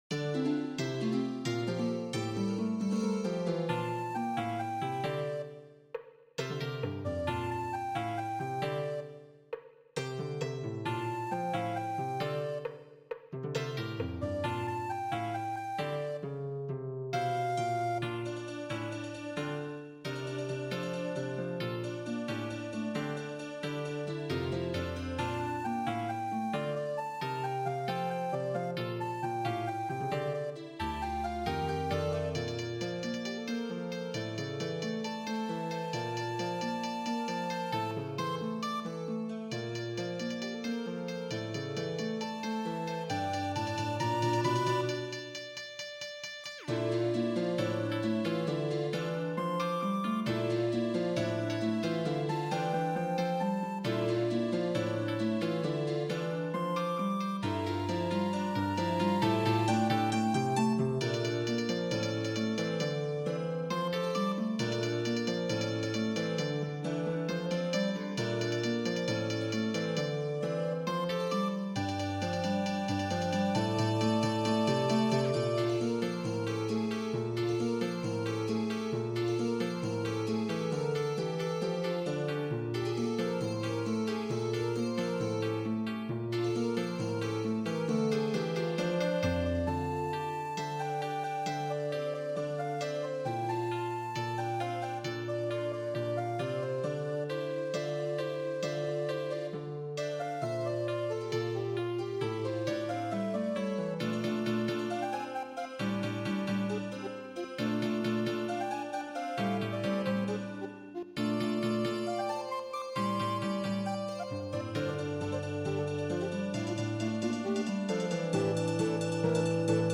パート編成 尺八・三味線・箏・十七絃合奏